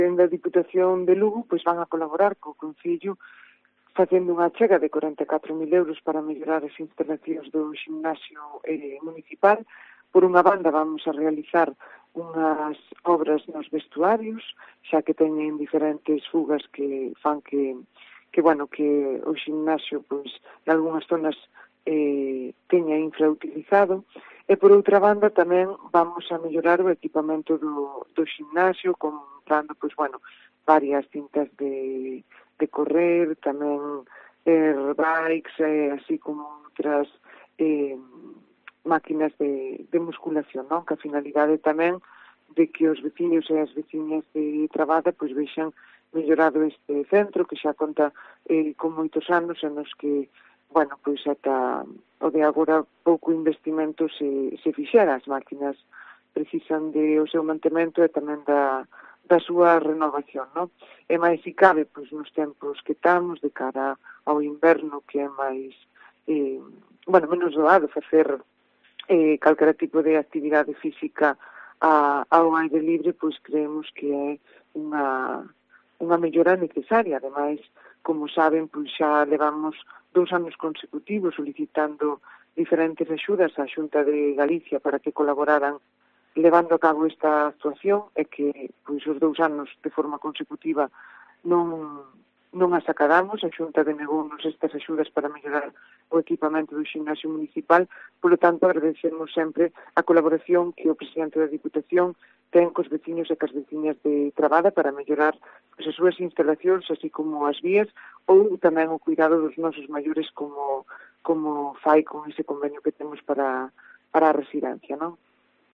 Declaraciones de Mayra García, alcaldesa de Trabada